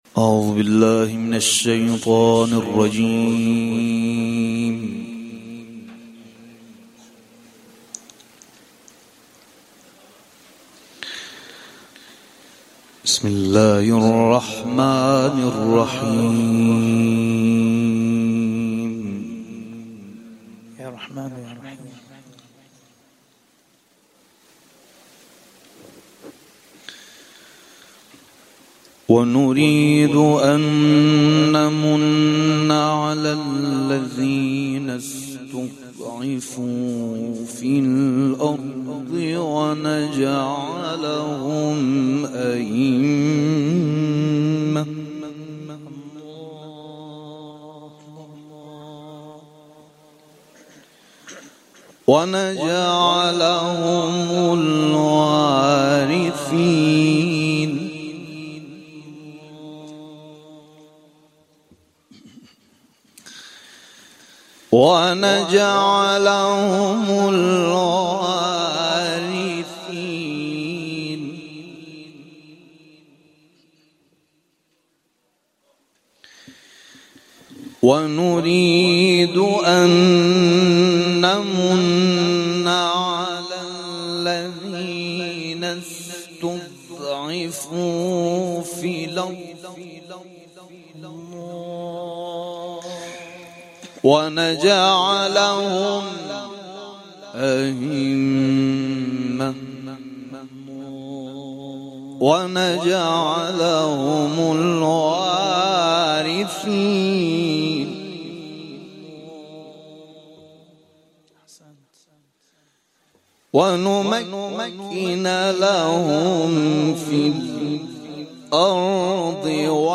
گزارش صوتی یکصد و هفتاد و چهارمین کرسی تلاوت و تفسیر قرآن کریم - پایگاه اطلاع رسانی ضیافت نور
قرائت سوره مبارکه قصص از آیه ۵ و سوره های لیل، ضحی، انشراح و کوثر